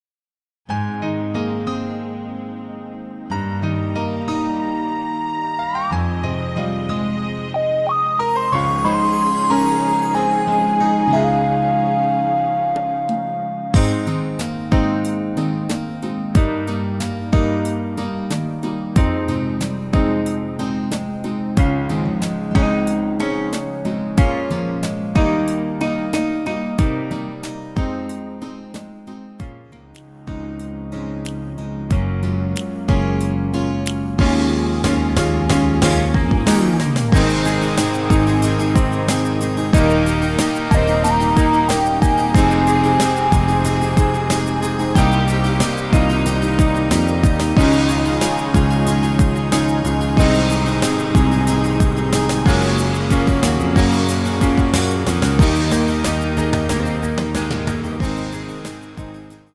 MR입니다.
키 Ab
원곡의 보컬 목소리를 MR에 약하게 넣어서 제작한 MR이며